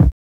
HIP HOP SMOO.wav